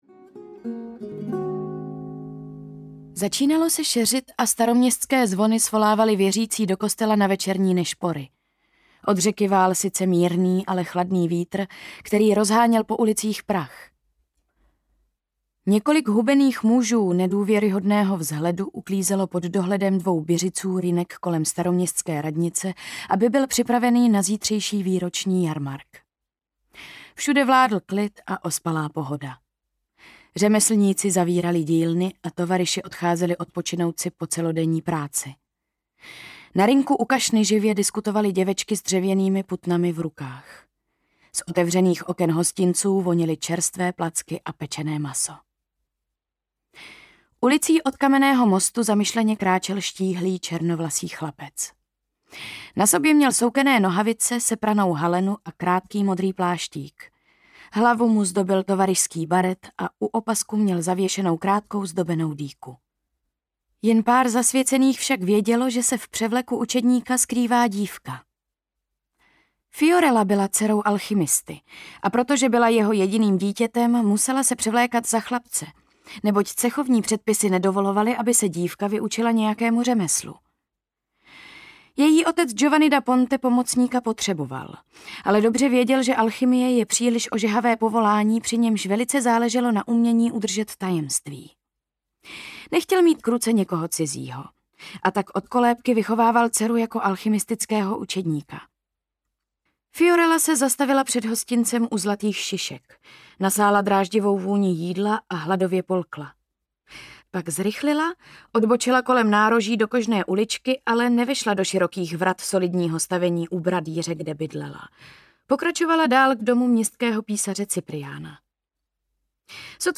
Záhada mrtvého netopýra audiokniha
Ukázka z knihy